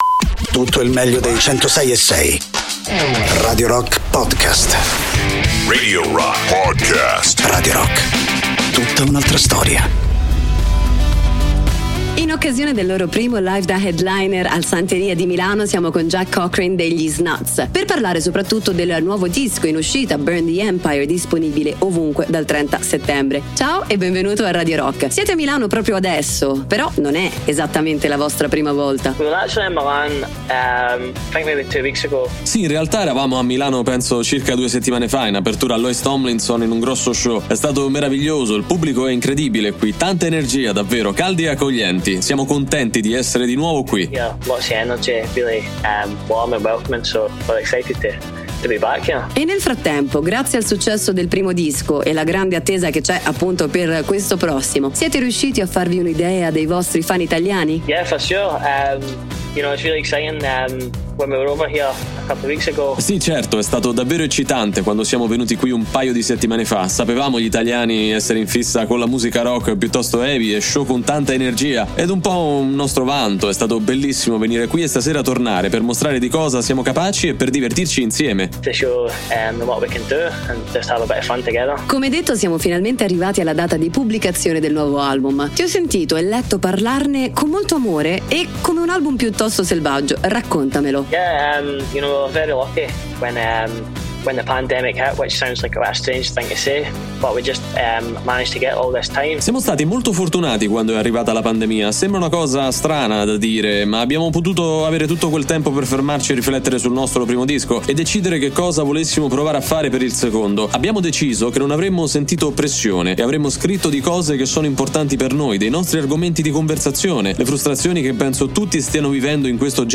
Interviste: The Snuts (05-10-22)